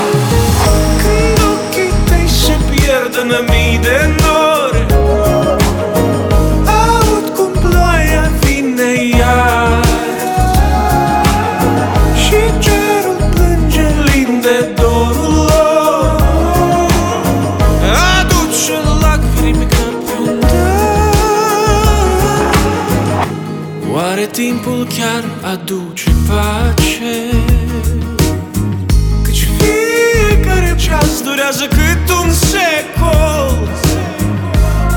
Жанр: Поп / Инди / Альтернатива